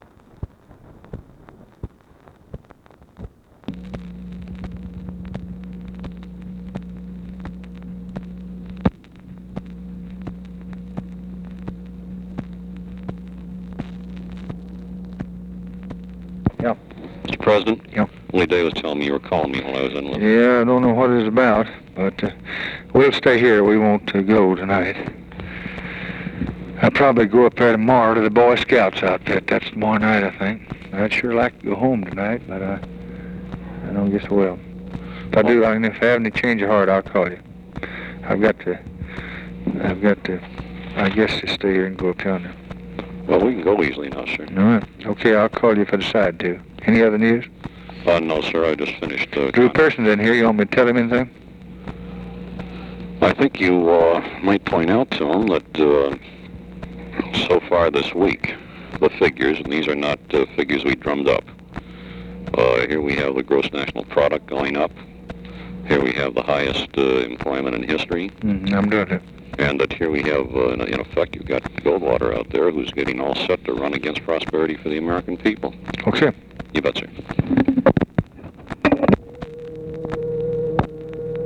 Conversation with GEORGE REEDY, July 16, 1964
Secret White House Tapes